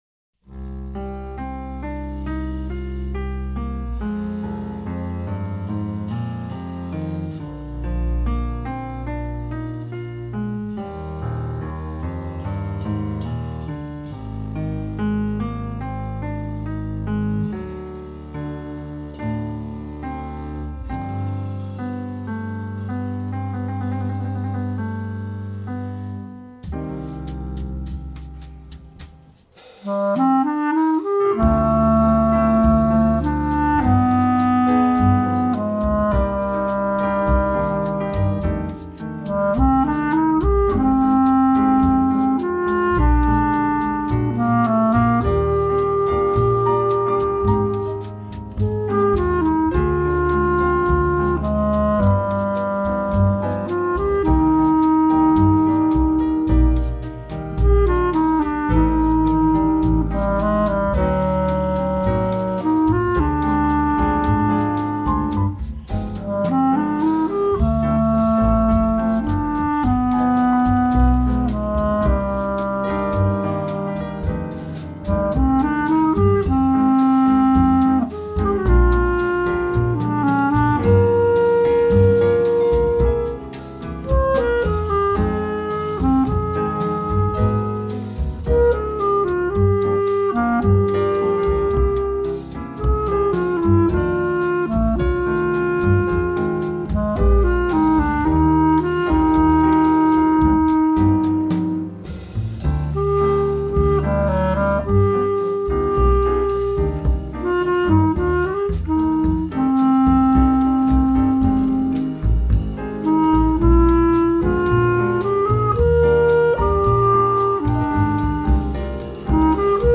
セッティングは、下記の通りで、カラオケCDの楽譜を、曲を聴きながら演奏して、あとで重ねました。
録音環境 楽器から30cmぐらいで録音
（息を満たした演奏バージョン）
とは言っても、「フォルテ」のイメージではなく、楽器の管内に息を満ちさせるようなイメージです。
やはりこちらのほうが、音色がいきいきとしますね。
反応がよいほうのリードを選んだのですが、やはり厚めなので、一部音の出だしがイマイチになっちゃってますね。